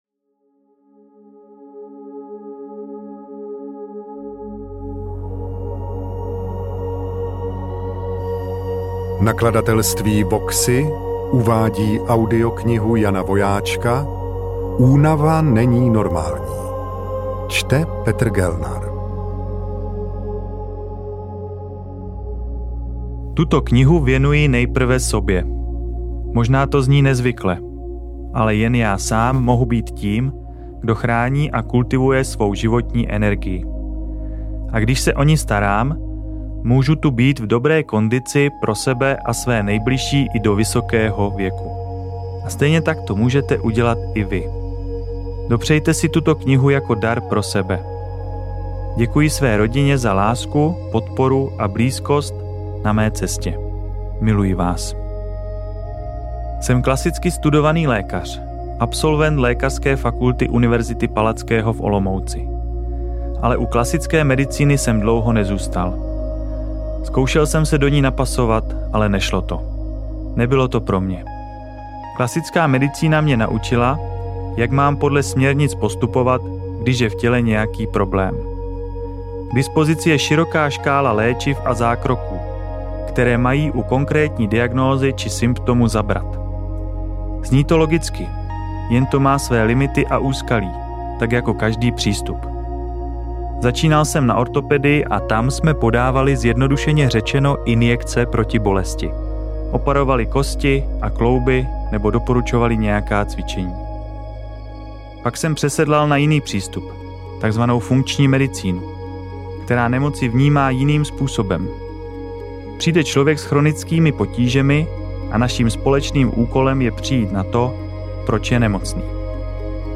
AudioKniha ke stažení, 18 x mp3, délka 8 hod., velikost 438,9 MB, česky